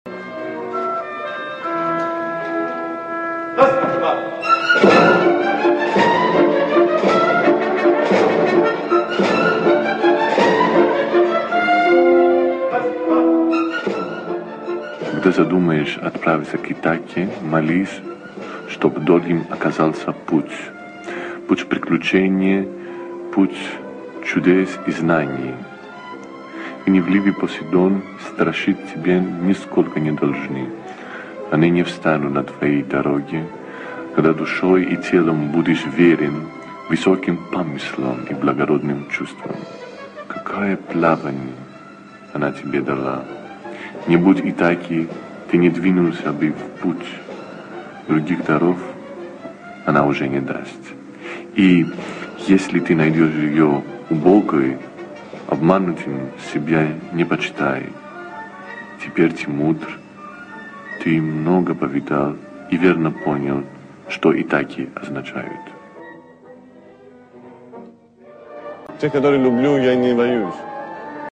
1. «Курентзис читает Кавафиса – “Итака”, отрывки» /